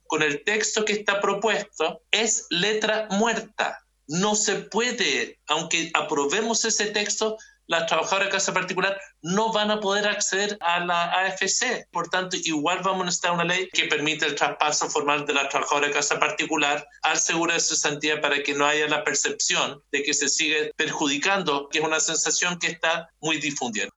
En ese sentido, el senador socialista Juan Pablo Letelier, que preside la comisión de Trabajo, señaló que la aplicación de la norma es inviable.